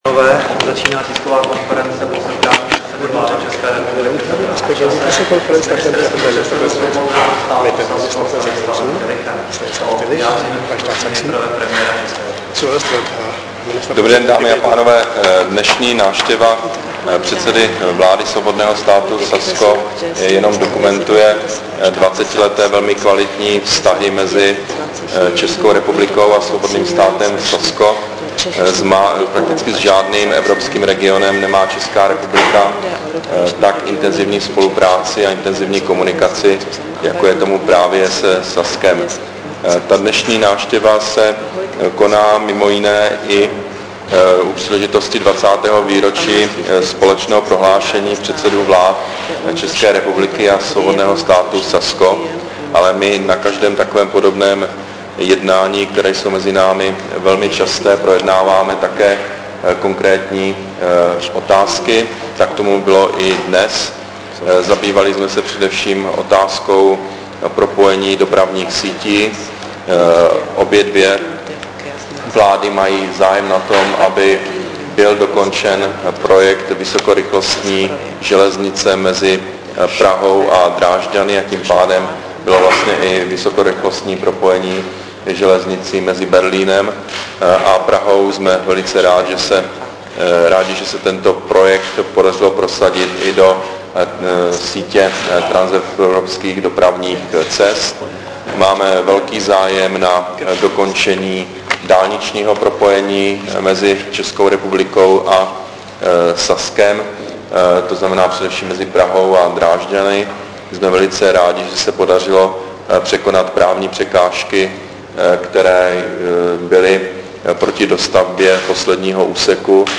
Tisková konference po setkání premiéra Petra Nečase s ministerským předsedou státu Sasko Stanislawem Tillichem, 7. prosince 2012